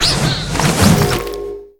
Cri de Forgella dans Pokémon HOME.